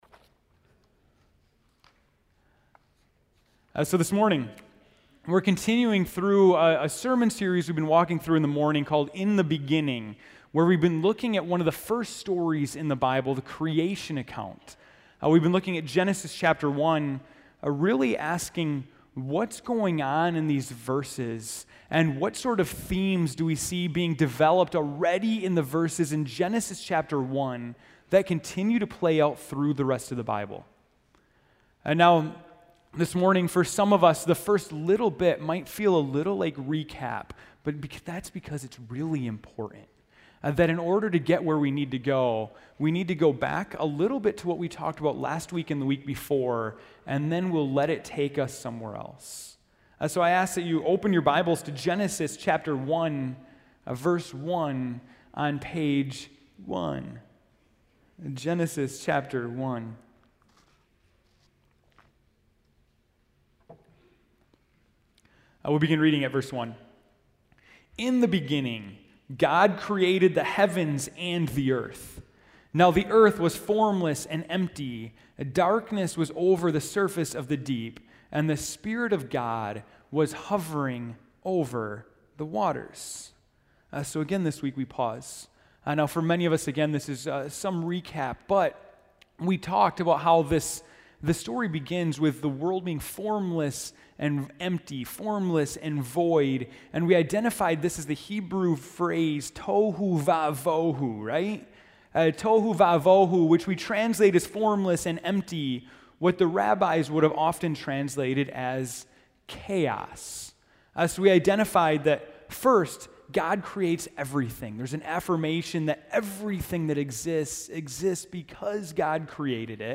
September 22, 2013 (Morning Worship)